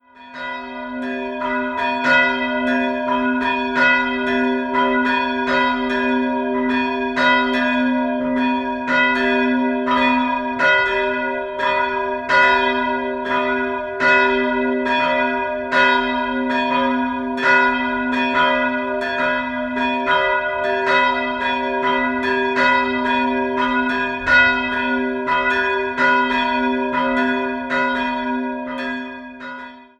Die Altäre stammen aus der Zeit um 1720. 2-stimmiges Geläut: h'-gis'' Die kleine Glocke wurde im Jahr 1401 gegossen, die große im Jahr 1921 von Karl Hamm (Regensburg).